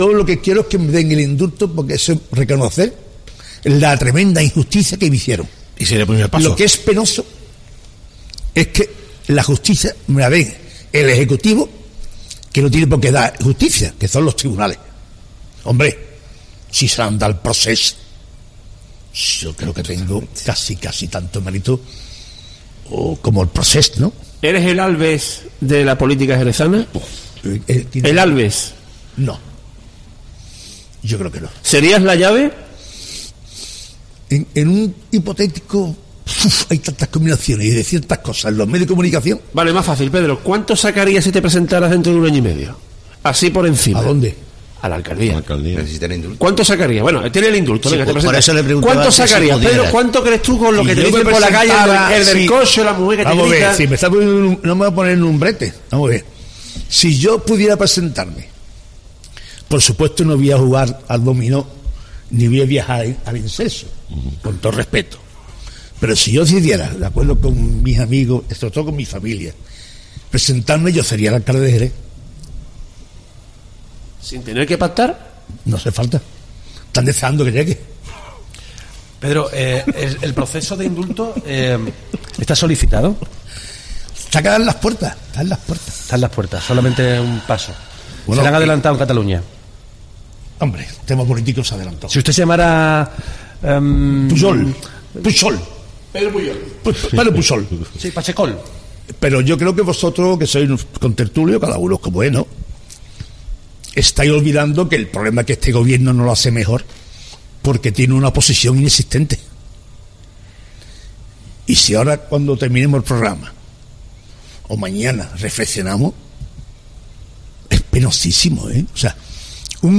A nadie ha dejado indiferente la intervención del ex alcalde de Jerez, Pedro Pacheco, en la jornada de ayer en El Tertulión de Cope Jerez.